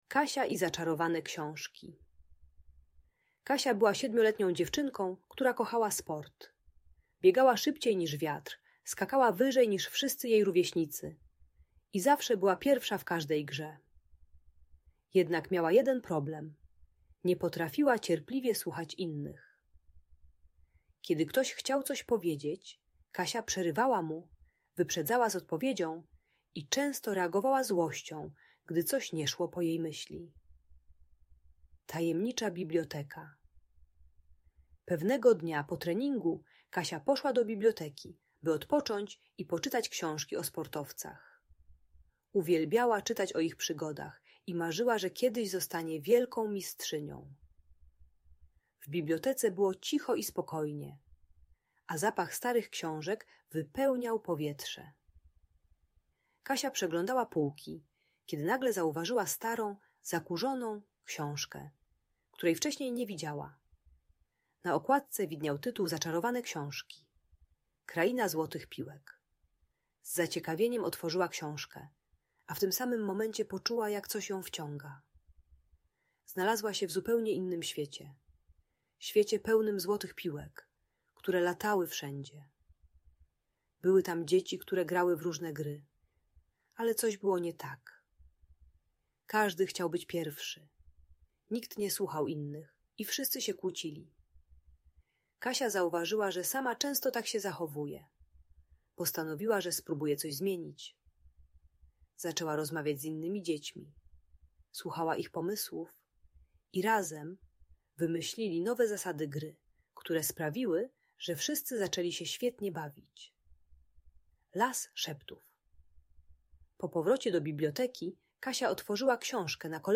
Kasia i Zaczarowane Książki: Historia o Słuchaniu - Audiobajka dla dzieci